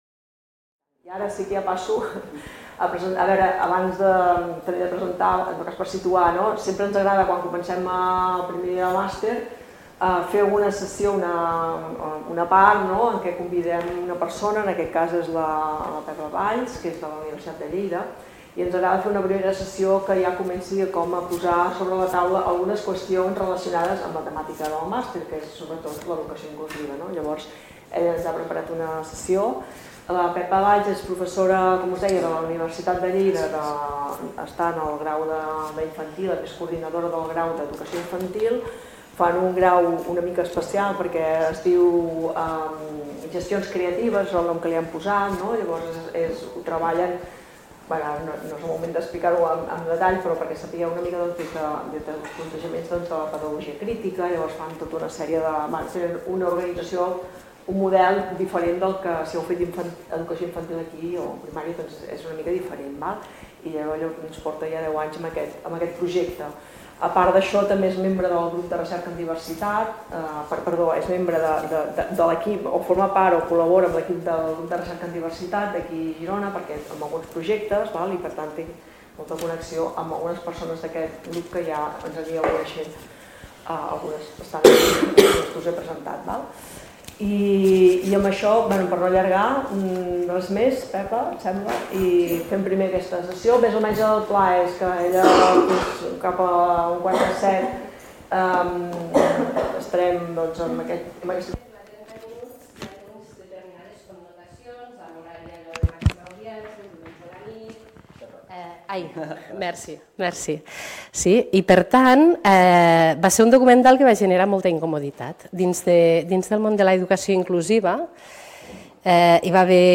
Educació Inclusiva. Conferència inaugural del Màster en Atenció a la Diversitat